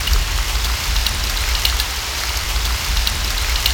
50 RAIN   -R.wav